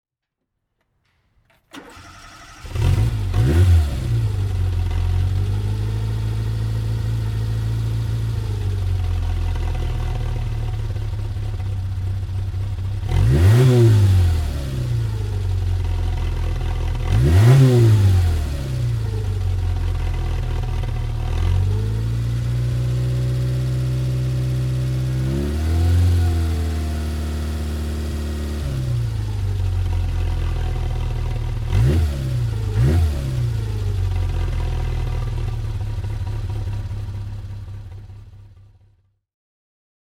Motorsounds und Tonaufnahmen zu Autobianchi Fahrzeugen (zufällige Auswahl)
Lancia Autobianchi A112 Abarth 70 HP (1985) - Starten und Leerlauf